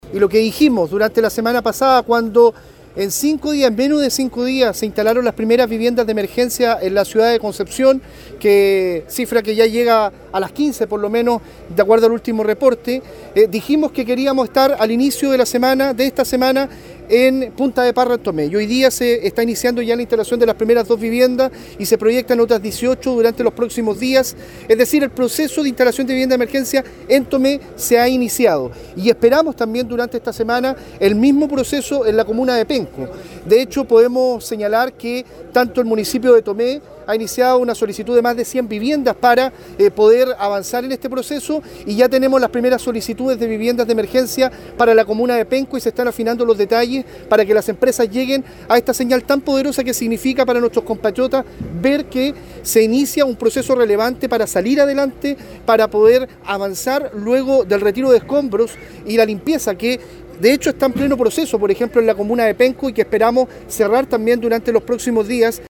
A su vez, el delegado presidencial regional, Eduardo Pacheco, enfatizó que, tal como se indicó la semana pasada, este lunes comenzó el proceso de instalación de 2 viviendas en Tomé, con al menos 18 casas en estos primeros días.